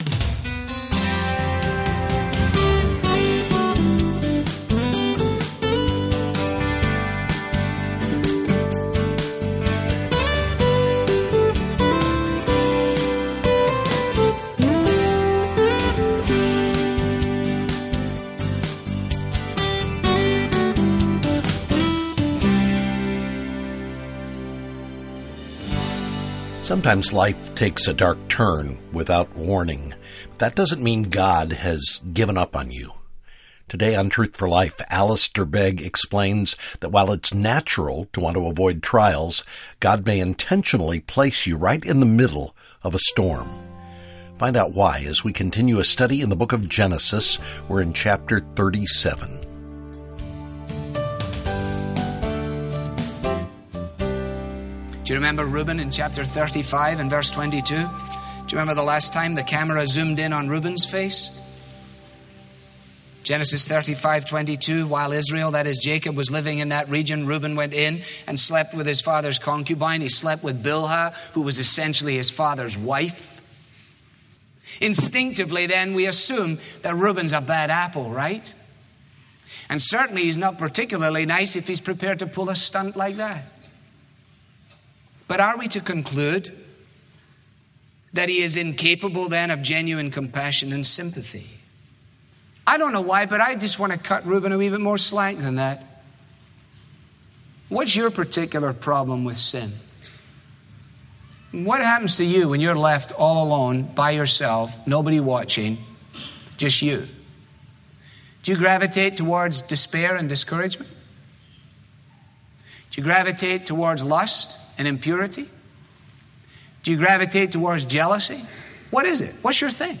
Teaching
Bible teaching